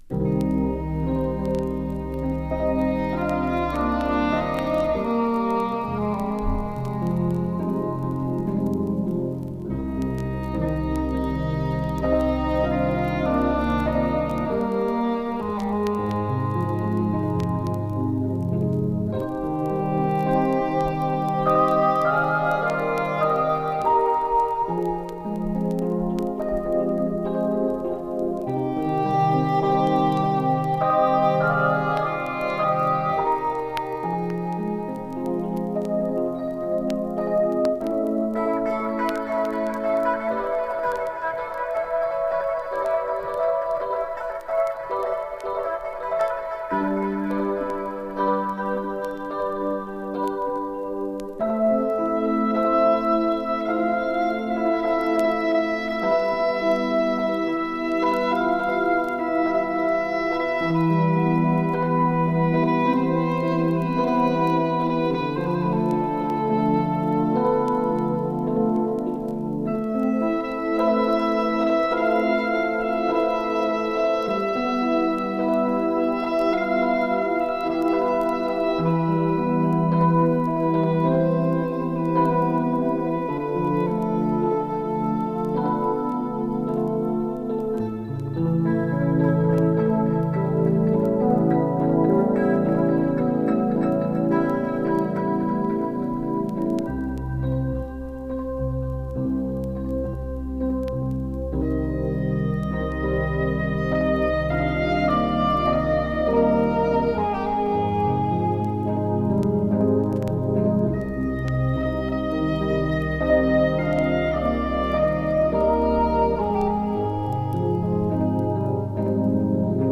Ambient Electronic Indie